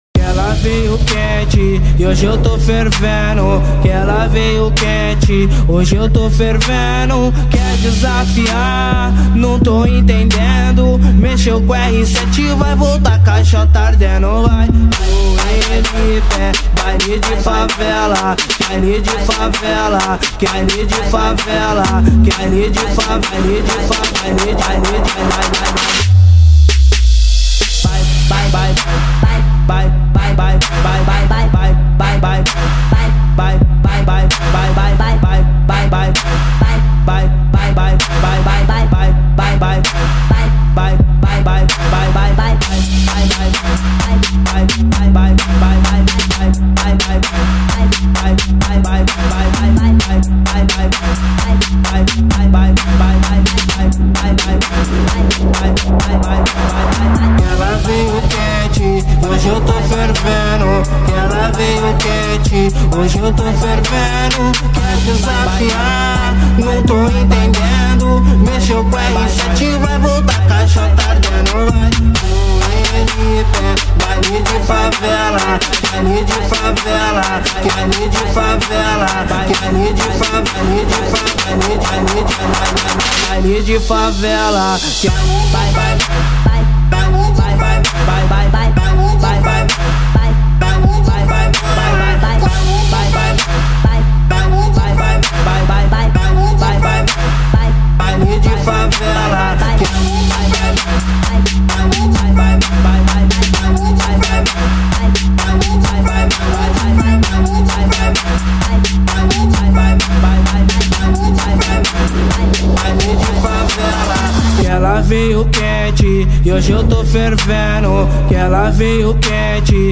Trap Funk Bass Boost